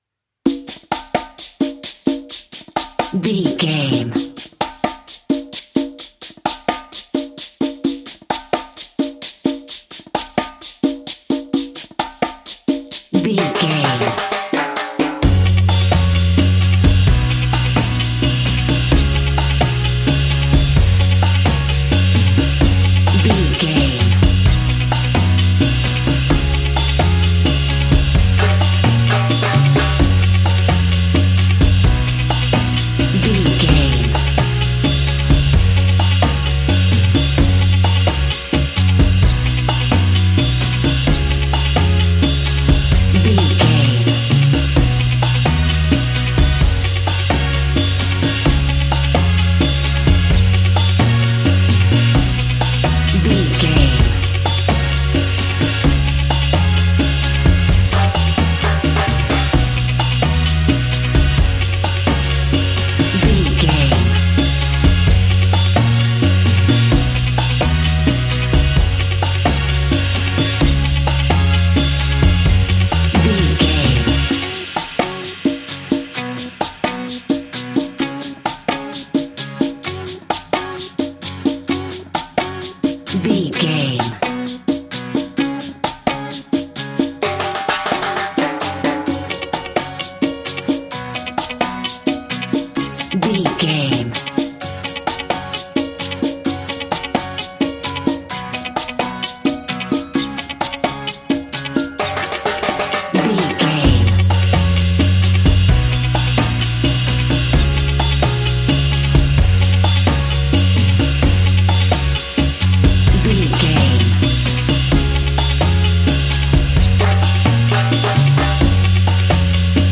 Uplifting
Ionian/Major
F#
steelpan
worldbeat
drums
percussion
bass
brass
guitar